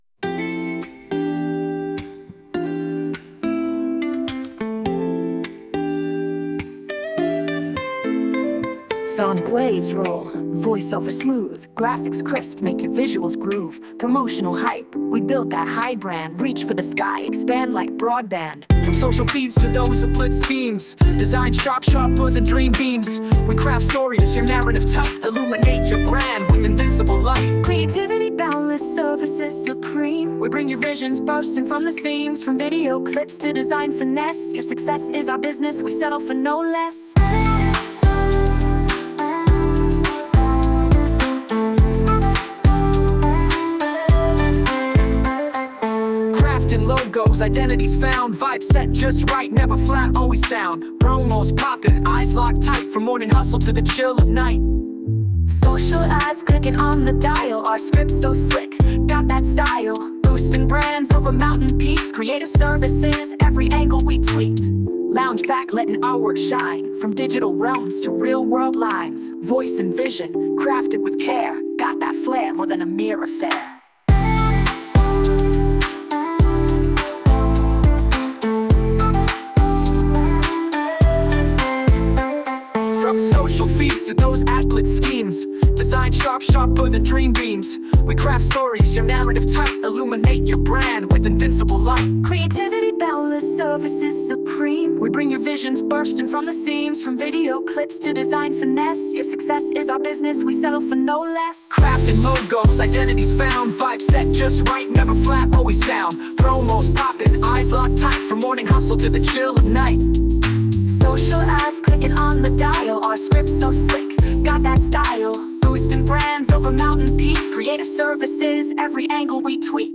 Music Genre: Hip-Hop (Duo Song)